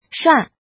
怎么读
shua4.mp3